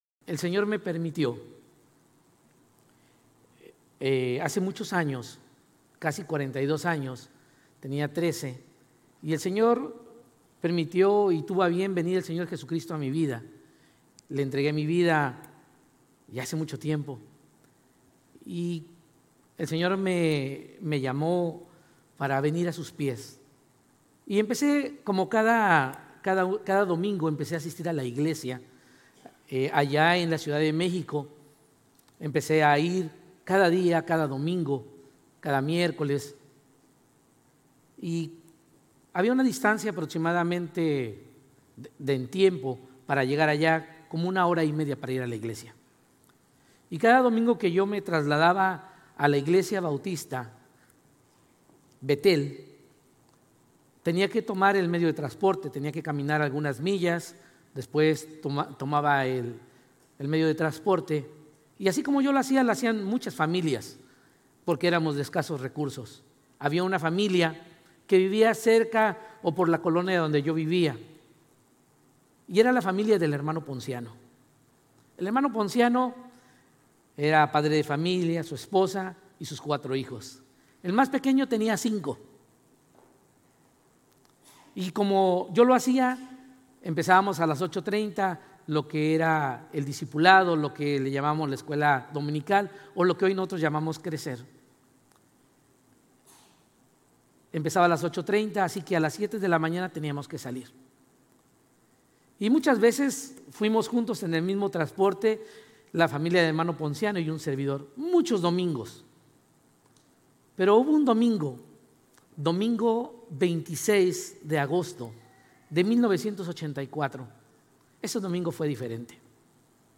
Sermones Grace Español 3_2 Grace Espanol Campus Mar 03 2025 | 00:32:52 1x 00:00 / 00:32:52 Subscribe Share RSS Feed Share Link Embed